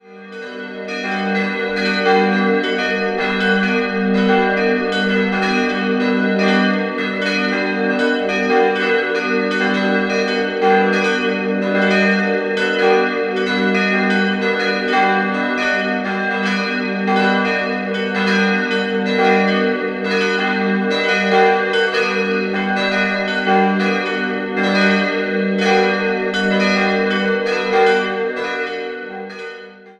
4-stimmiges Geläut: g'-h'-dis''-fis'' Die beiden größeren Glocken wurden im Jahr 1591 in Nürnberg von Christof Glockengießer gegossen.